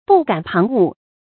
不敢旁骛 bù gǎn páng wù
不敢旁骛发音